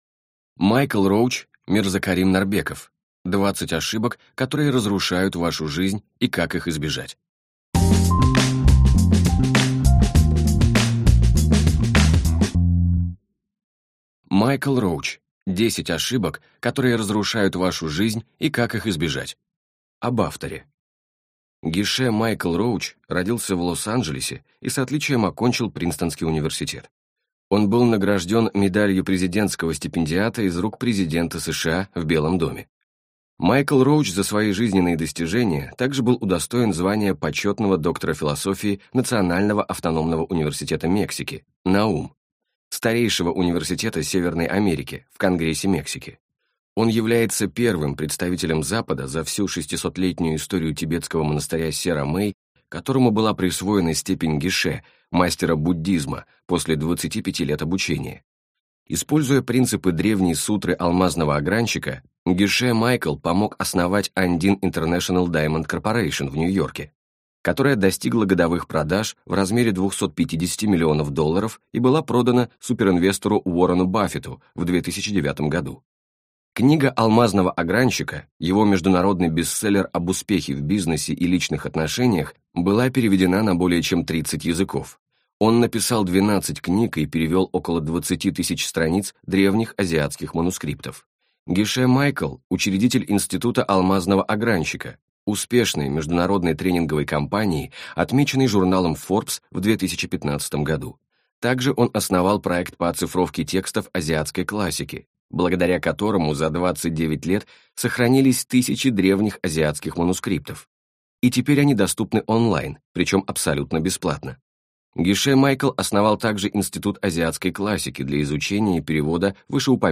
Аудиокнига 20 ошибок, которые разрушают вашу жизнь, и как их избежать | Библиотека аудиокниг